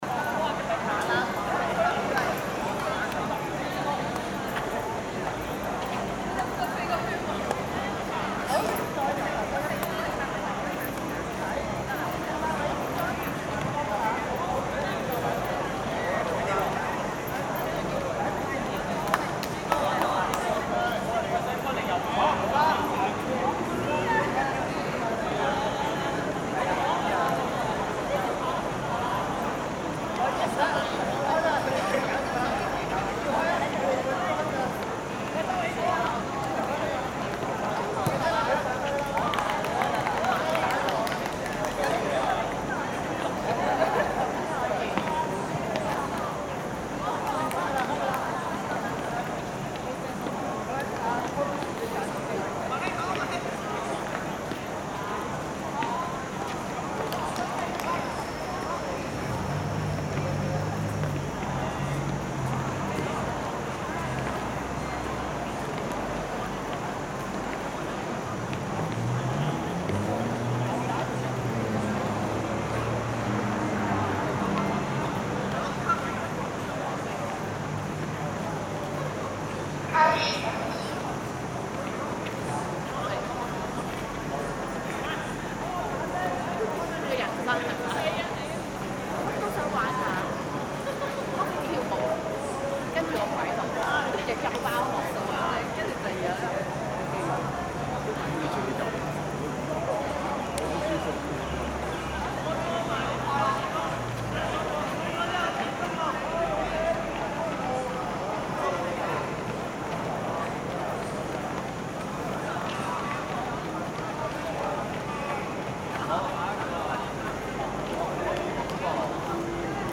Category Field Recording
Announcement , Cicada , Football , Jogging , Shouting , Talking
錄音地點位於看台上，錄音中可以聽到一群人慢跑的聲音、踢足球者的大叫聲，以及蟬鳴的聲音。
The sports ground features a covered stand, a grass football field, and a 400-meter running track, providing a variety of sports facilities for the community. The recording location is situated on the stand, where the sounds of a group of people jogging, the shouts of football players, and the chirping of cicadas can be heard.